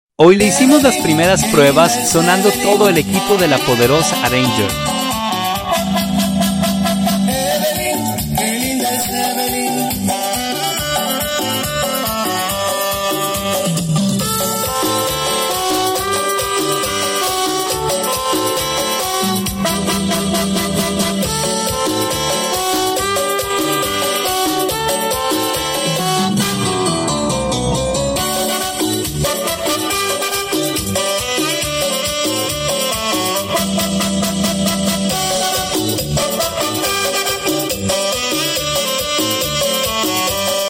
este día le hicimos las primeras pruebas a la poderosa Ranger sonando el equipo con cumbias para ver qué tan se escuchaba aún faltan ajustes